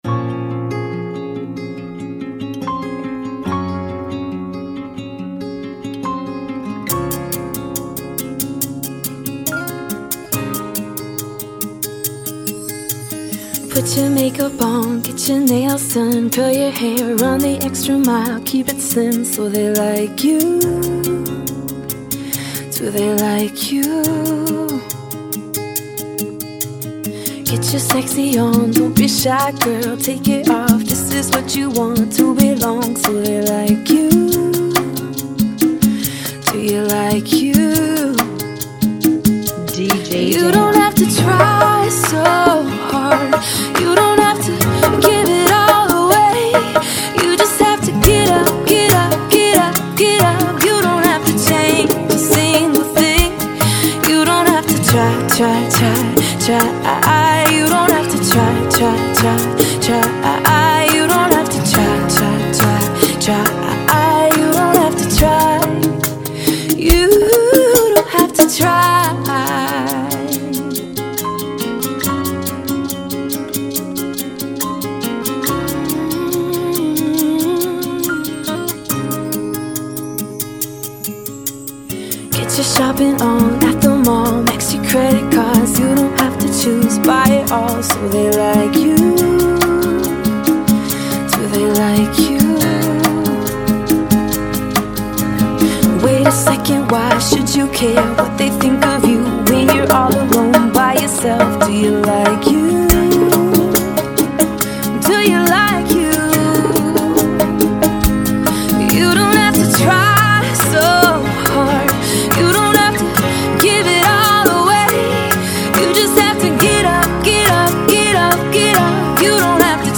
140 BPM
Genre: Bachata Remix